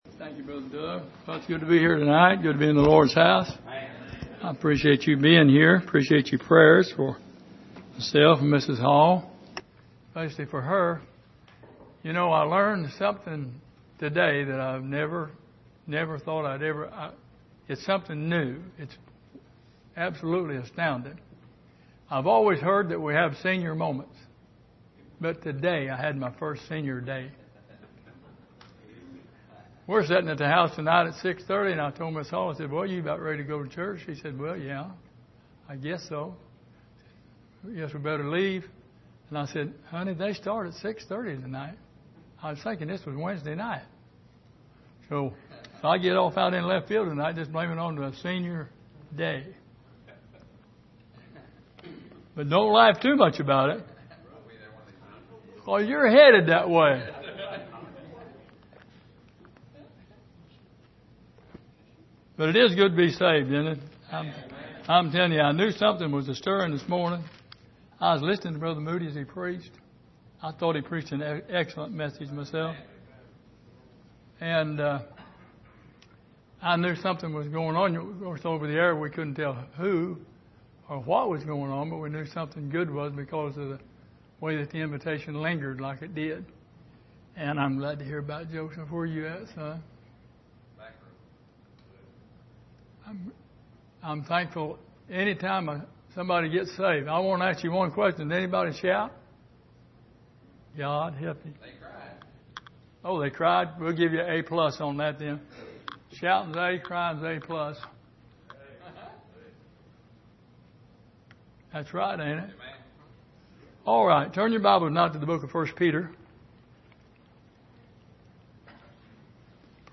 Passage: 1 Peter 3:8-13 Service: Sunday Evening